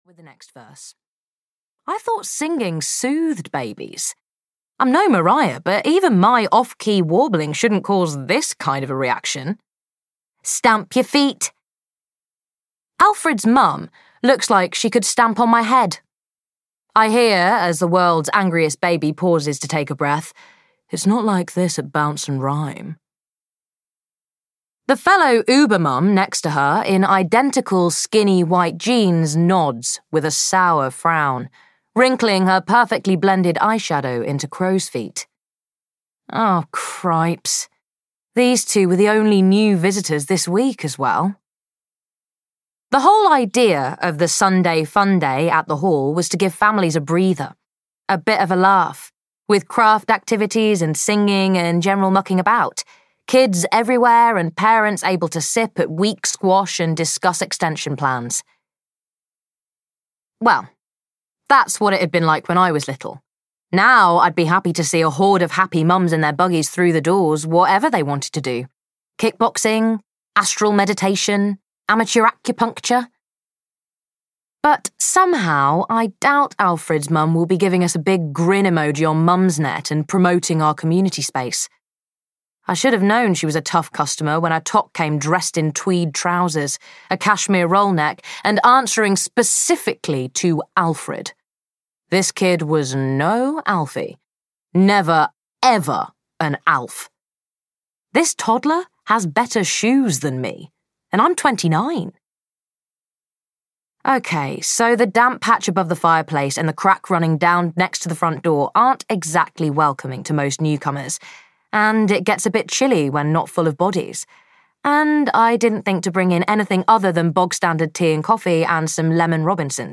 The Bluebell Bunting Society (EN) audiokniha
Ukázka z knihy